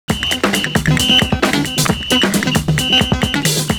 GUITAR N COP.wav